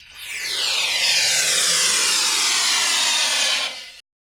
AIR RELEAS.wav